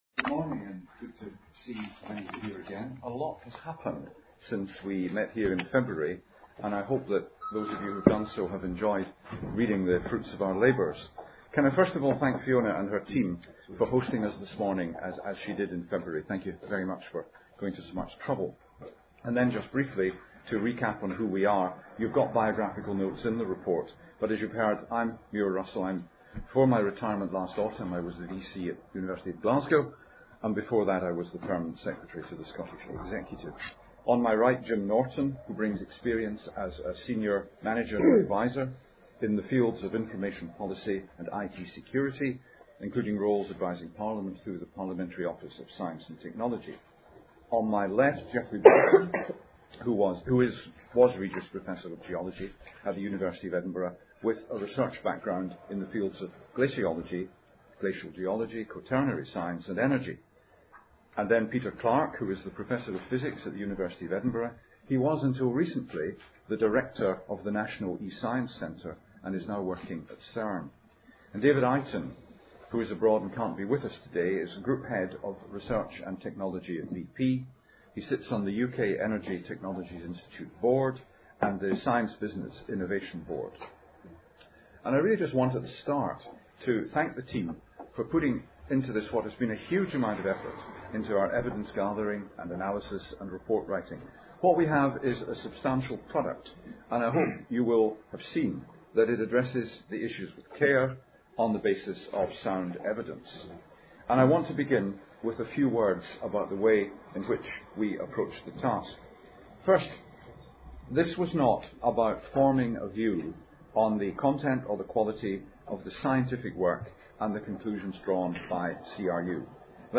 cce-audio-of-press-conference.mp3